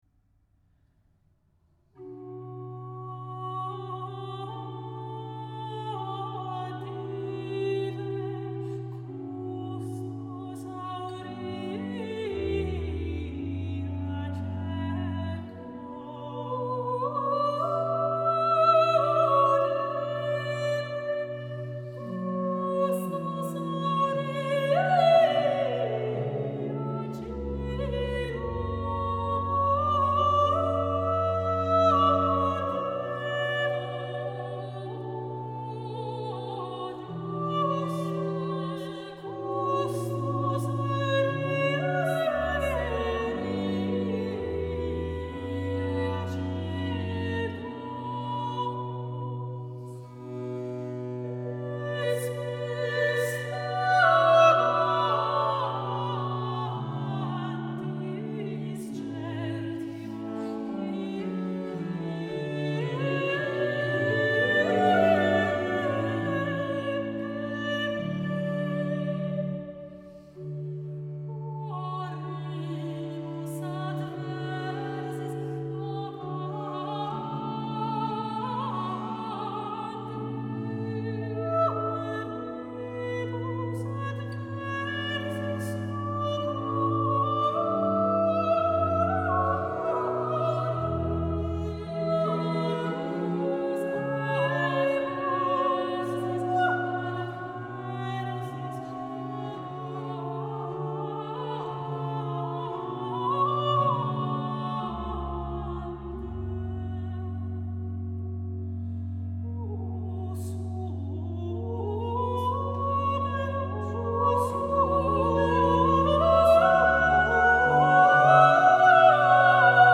Sopran
Viola da Gamba
Orgel